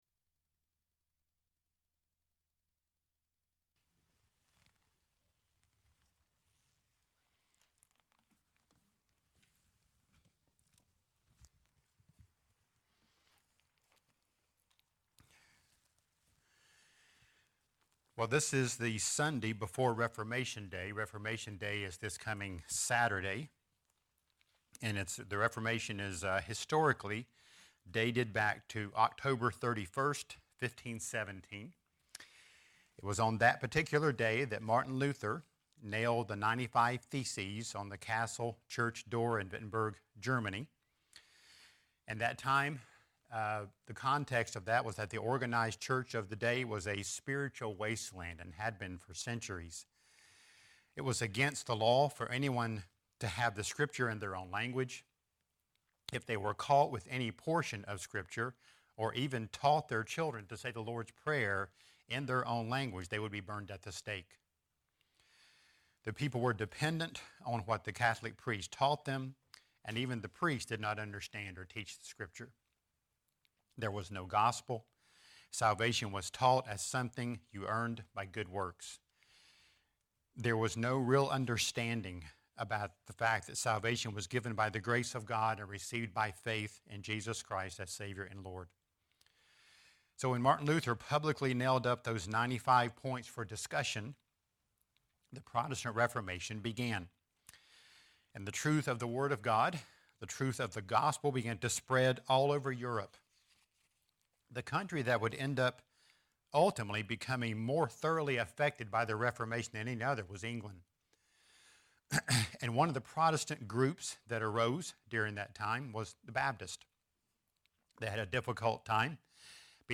A Biography of Samuel Pearce | SermonAudio Broadcaster is Live View the Live Stream Share this sermon Disabled by adblocker Copy URL Copied!